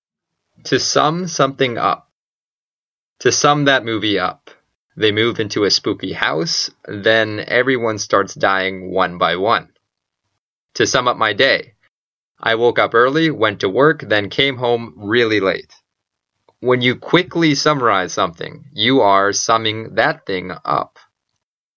英語ネイティブによる発音は下記のリンクをクリックしてください。